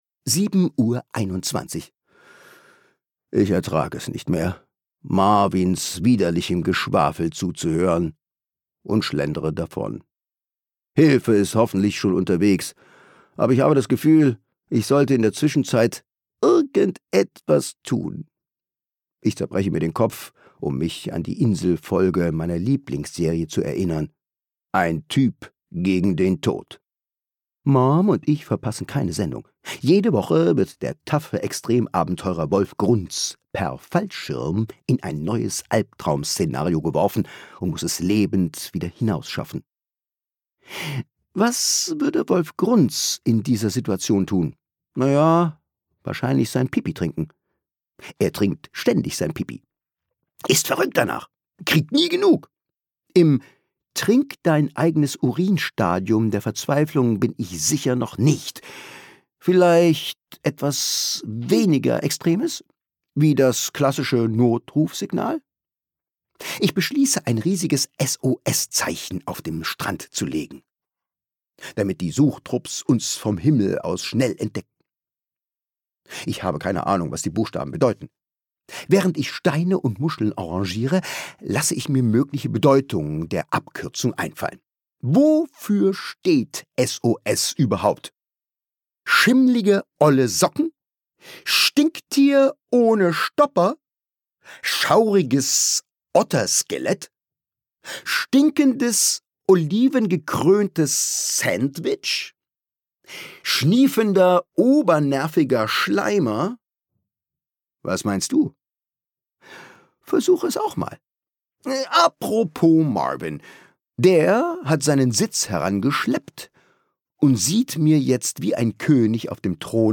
Schule, Peinlichkeiten und ganz viel Humor – Cooles Hörbuch für Kinder ab 10 Jahre
Worst Week Ever – Mittwoch Gelesen von: Thomas Nicolai